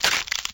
Neck Snap 2
NeckSnap2.mp3